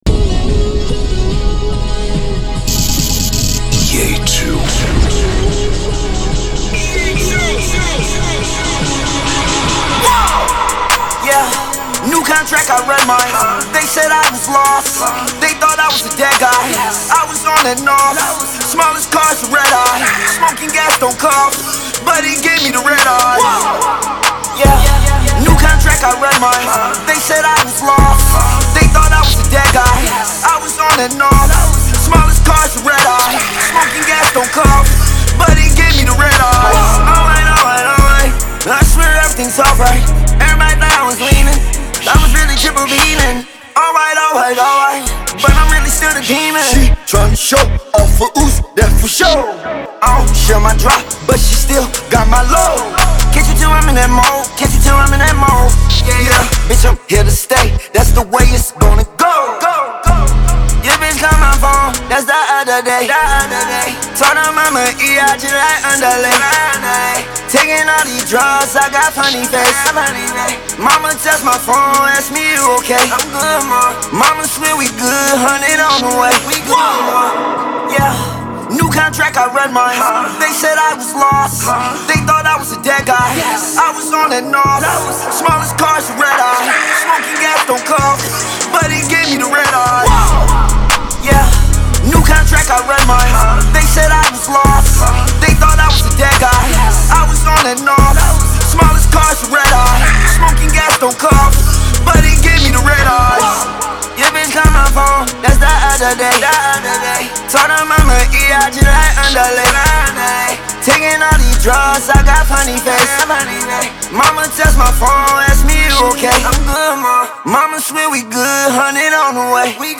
поп рэп